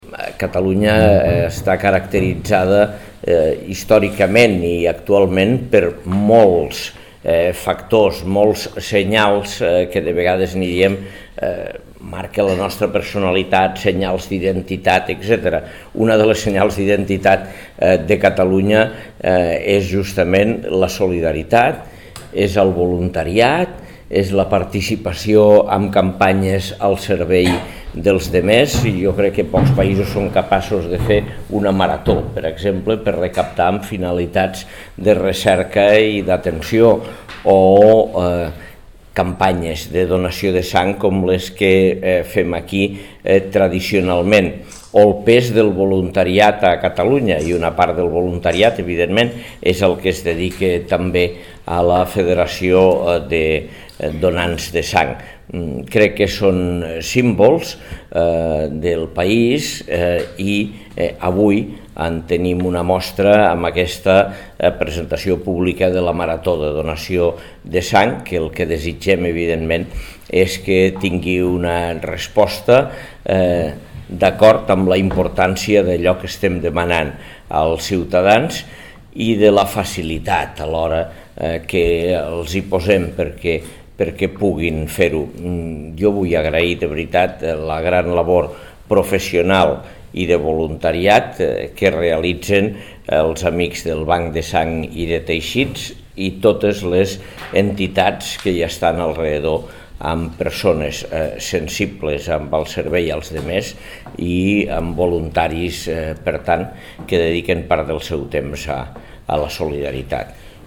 tall-de-veu-de-lalcalde-angel-ros-sobre-la-participacio-un-any-mes-de-lleida-en-la-marato-de-donants-de-sang-2.0